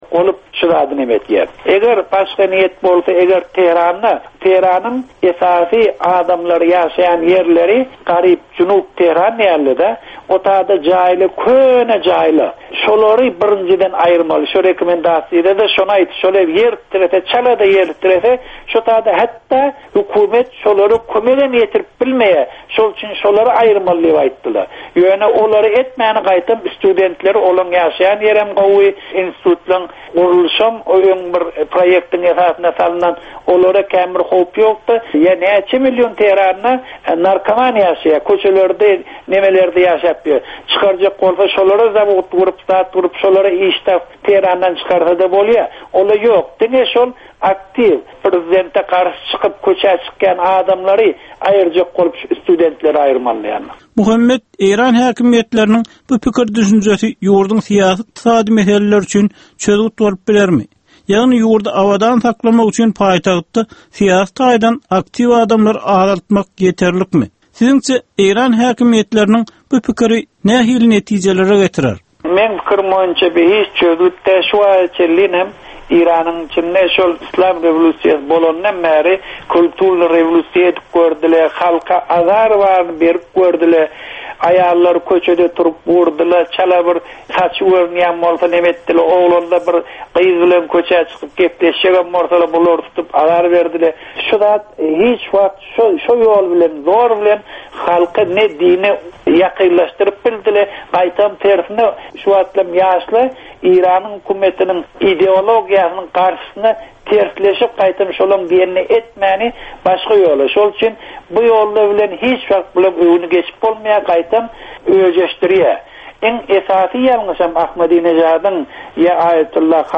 Türkmen jemgyýetindäki döwrüň meseleleri. Döwrüň anyk bir meselesi barada ýörite syn-gepleşik. Bu gepleşikde diňleýjiler, synçylar we bilermenler döwrüň anyk bir meselesi barada pikir öwürýärler, öz garaýyşlaryny we tekliplerini orta atýarlar.